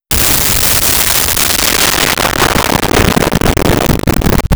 Explosion Medium
Explosion Medium.wav